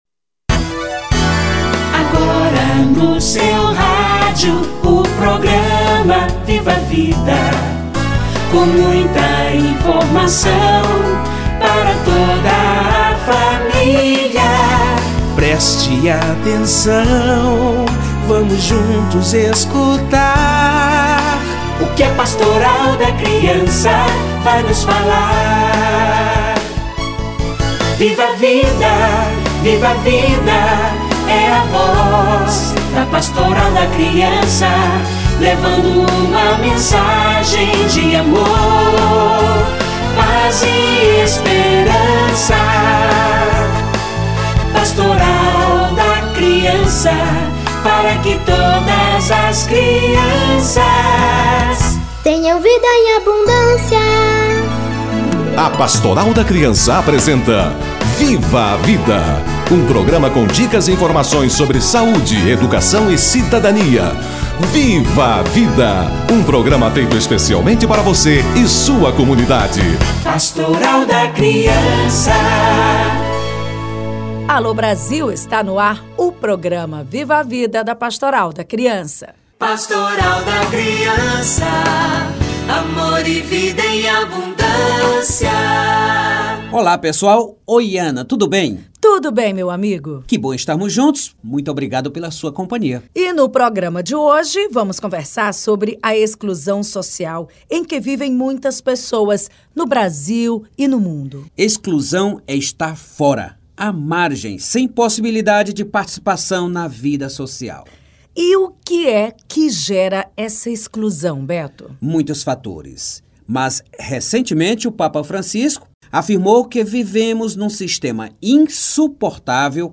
Meio ambiente e o Grito dos Excluídos - Entrevista